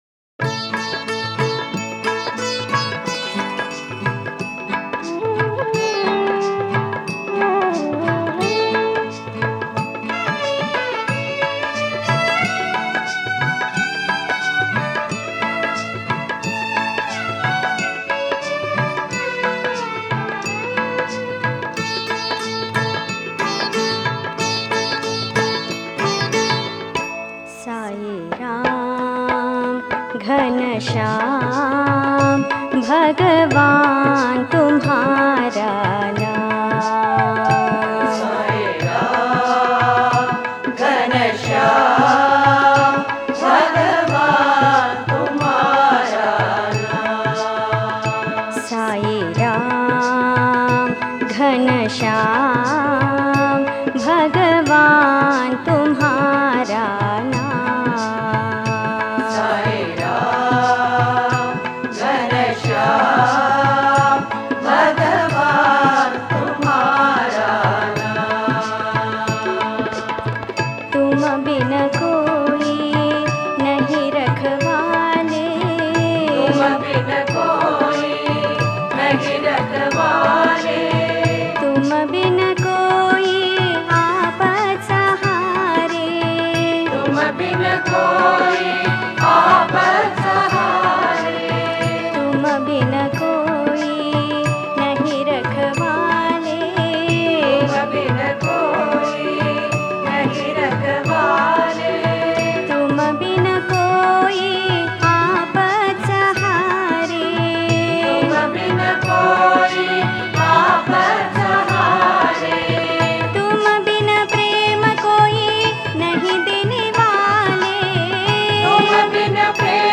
Author adminPosted on Categories Sai Bhajans